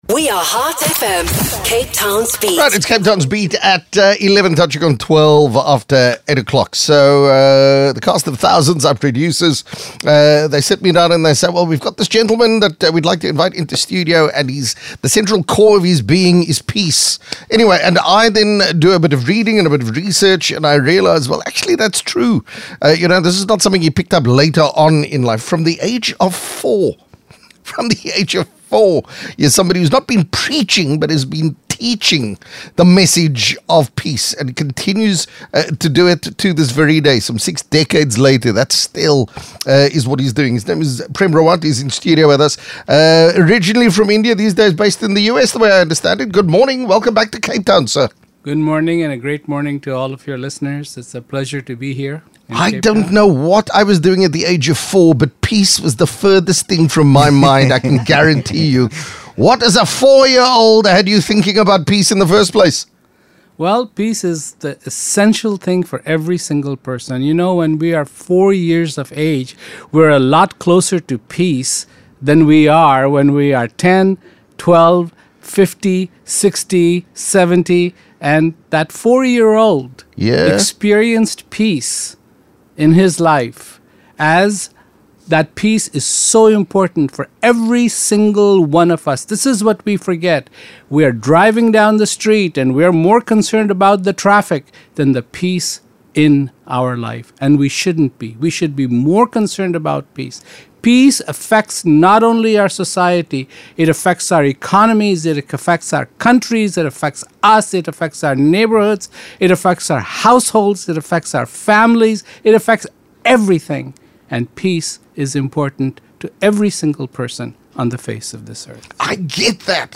Heart FM Radio Interview with Prem Rawat | Words of Peace
Listen to an interview with Prem Rawat on Cape Town’s Heart FM radio station on May 4th, 2023.